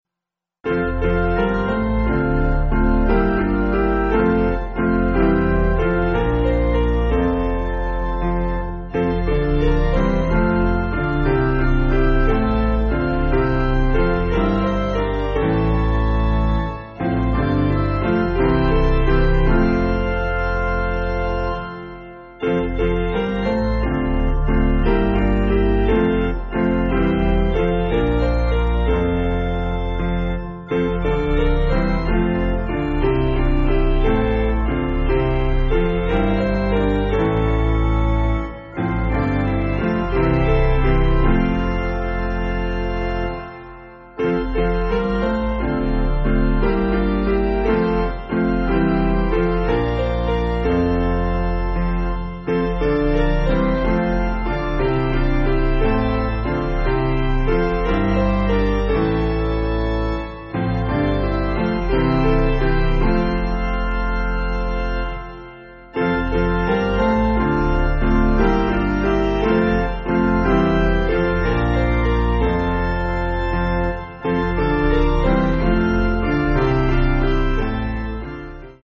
German Carol
Basic Piano & Organ